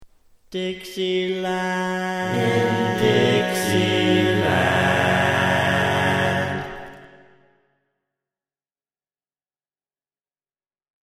Key written in: F# Major
How many parts: 4
Type: Barbershop